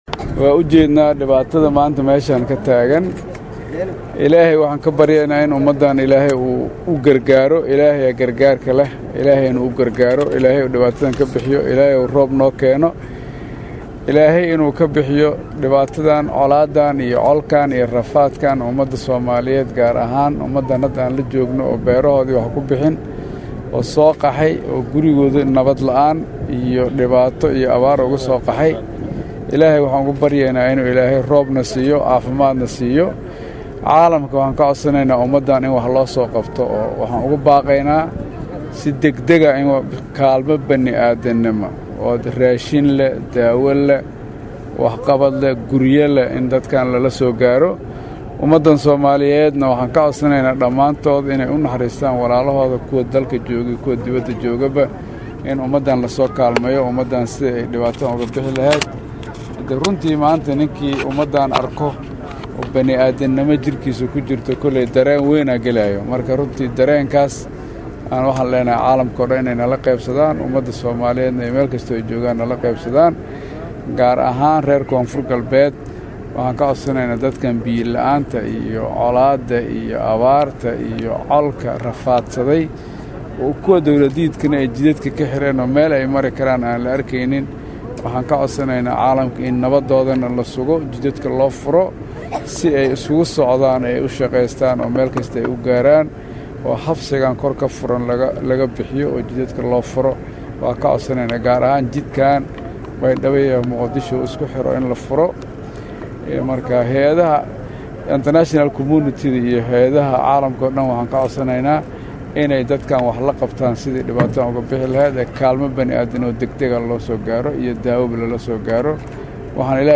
Shafiir Xasan oo kormeer ku tegey xero ay ku jiraan qoysas ka soo barakacay deegaannada ay abaartu ku dhufatay ayaa ka codsaday shacabka Soomaaliyeed meelkasta oo ay joogaan inay ka qeyb qaataan gurmad u fidinta dadka ay abaartu saameysay.
Halkan hoose ka dhageyso hadalka Shariif Xasan